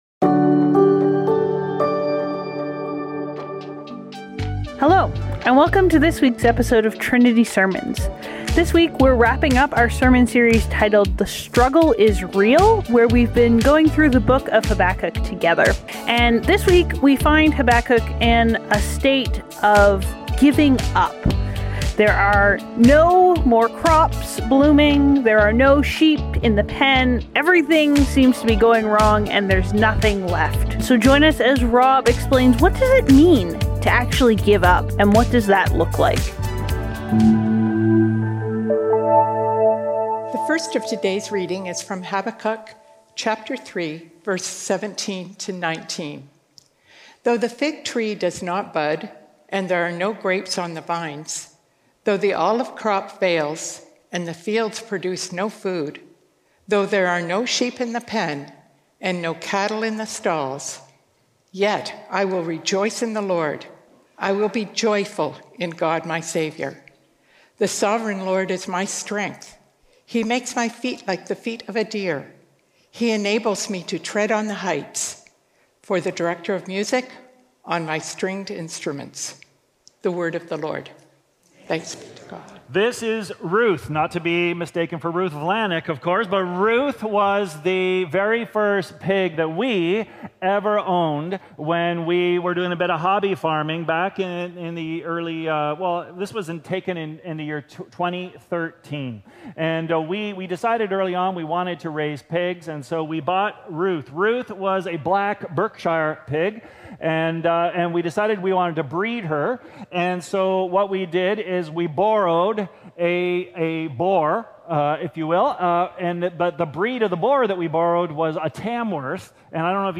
Trinity Streetsville - Give Up | The Struggle Is Real | Trinity Sermons